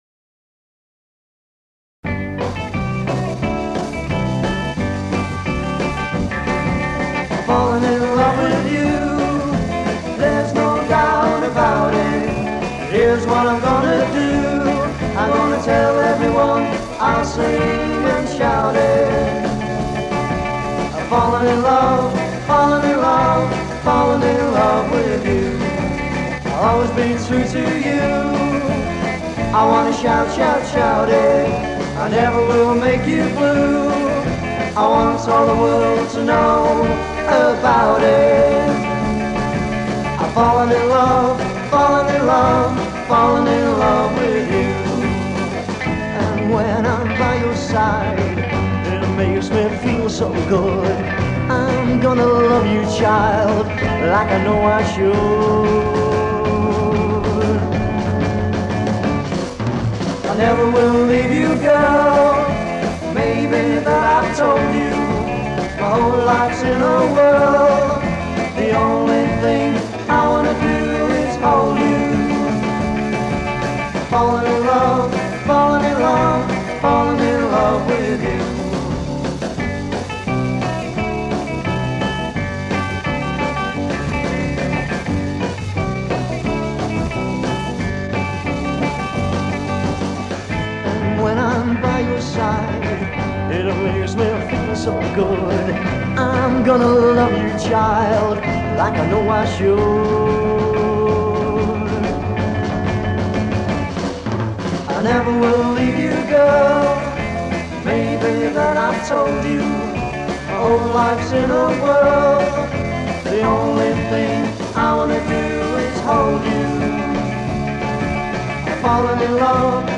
vocals
bass
drums
lead